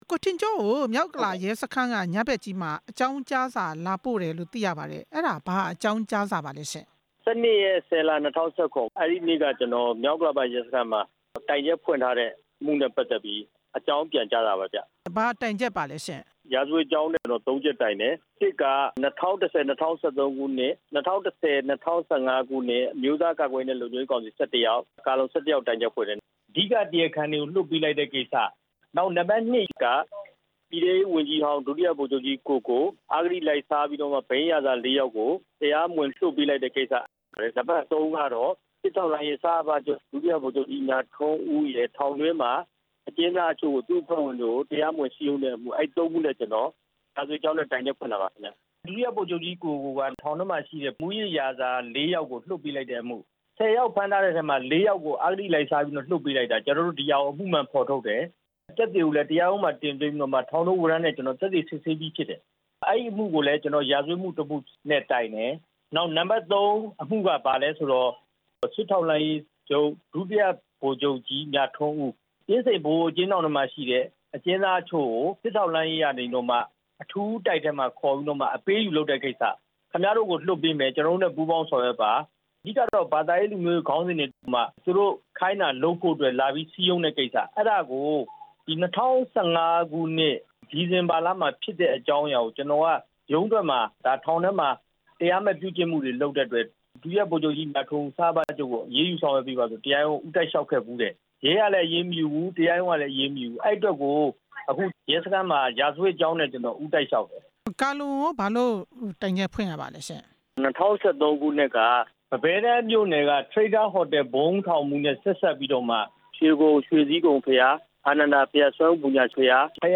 ဆက်သွယ် မေးမြန်းချက်